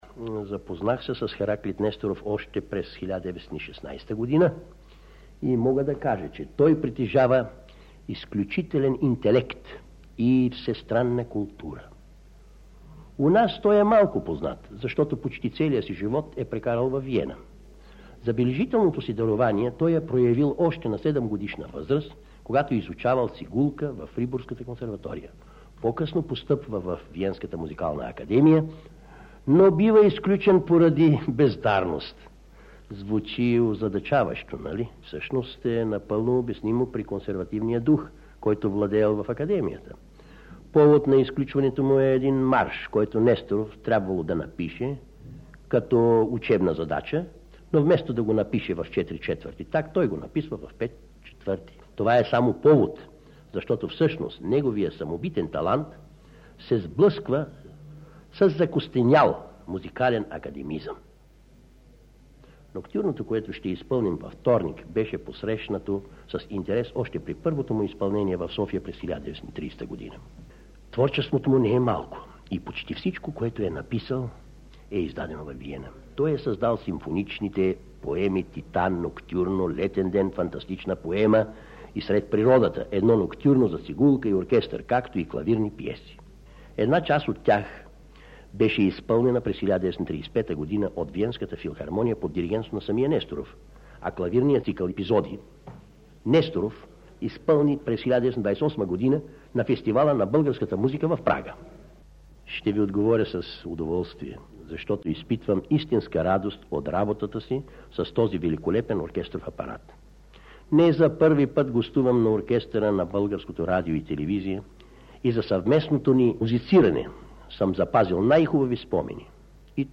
В единствения съхранен в Златния фонд на БНР запис на Саша Попов (вероятно от 50-те години на миналия век), той, като гост диригент на Симфоничния оркестър на Радио София, говори за един от авторите, включени в концертната програма – Хераклит Несторов и споделя удовлетворението си от работата с „този прекрасен оркестров състав“: